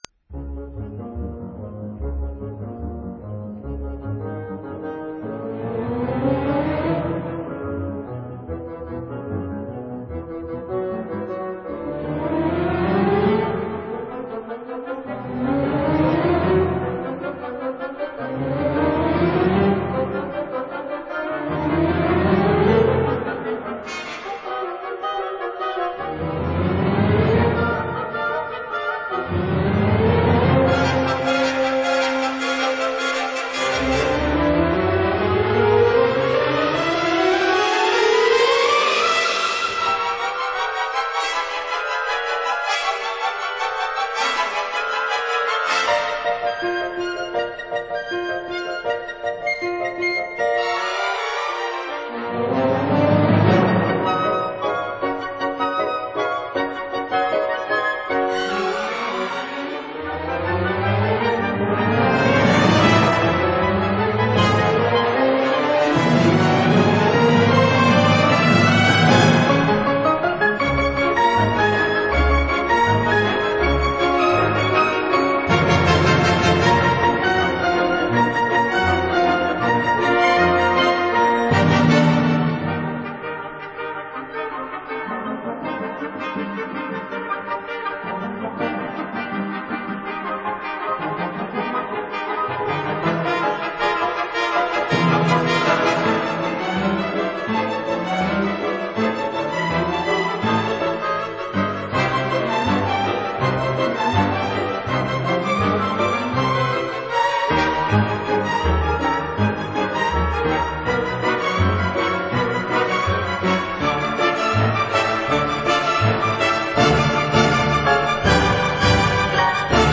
mechanical ballet
jazz-ballet